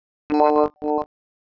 Ниже приведены временные реализации слова при разных условиях
Воспроизводится набор кадров после корреляционной обработки.
Соседние кадры отличаются друг от друга больше, чем на 500 условных единиц в метрике огибающей.